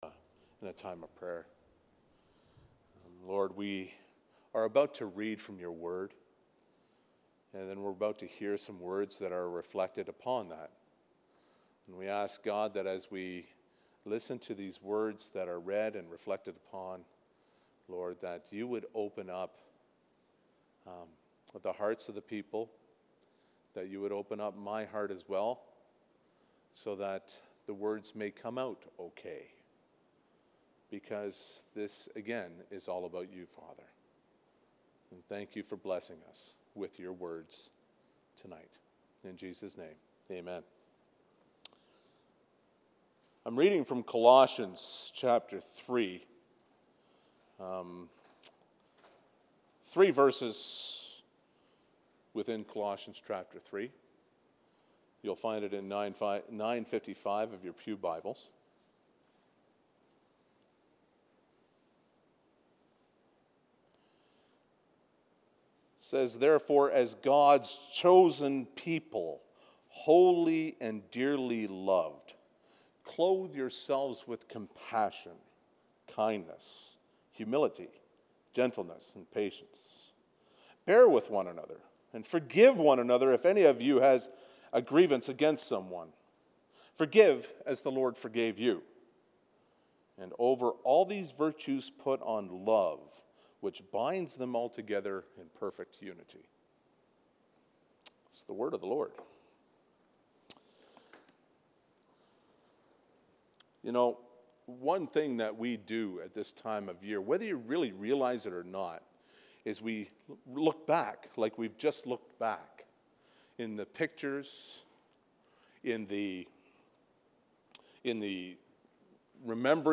Sermon Messages - Lucknow Community Christian Reformed Church